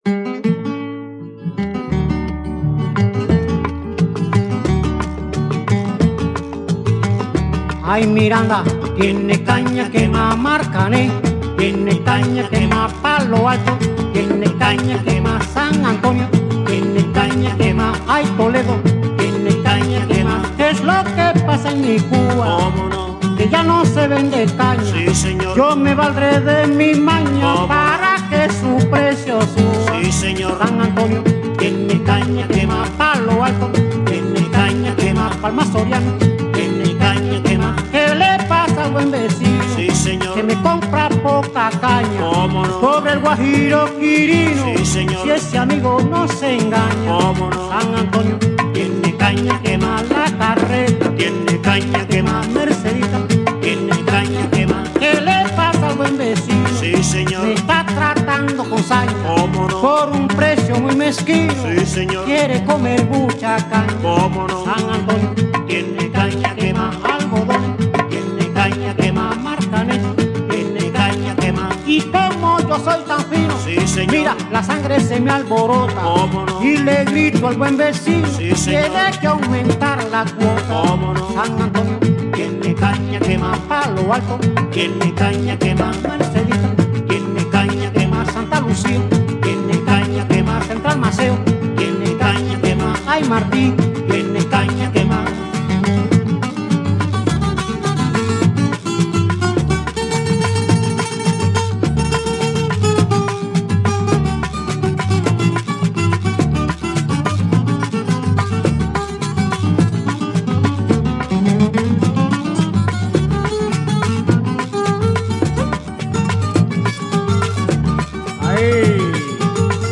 Son Montuno
SON-MONTUNO-SON-CUBANO-de-antano-TEMA-CROMOS-HISTORIA-DE-LOS-TRANSPORTES-Y-MARAVILLAS-DEL-ESPACIO.mp3